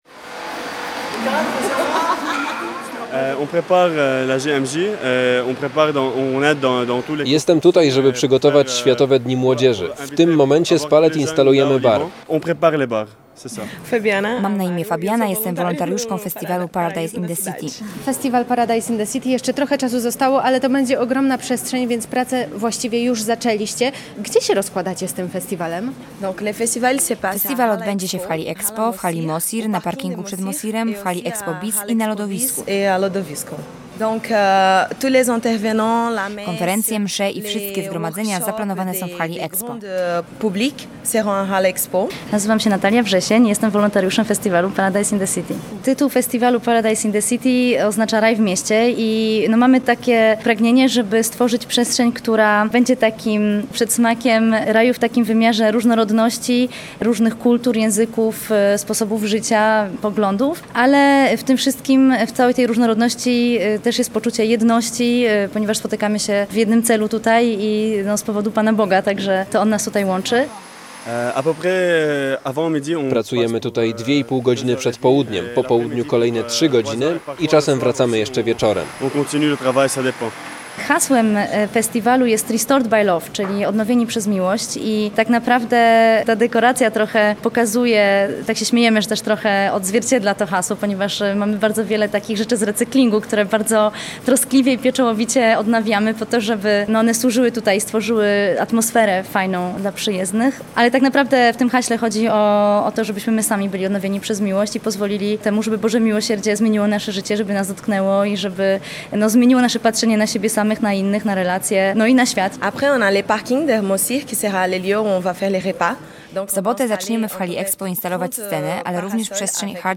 Posłuchaj relacji i dowiedz się więcej: Nazwa Plik Autor Miasteczko Miłosierdzia audio (m4a) audio (oga) ZDJĘCIA, NAGRANIA WIDEO, WIĘCEJ INFORMACJI Z ŁODZI I REGIONU ZNAJDZIESZ W DZIALE “WIADOMOŚCI”.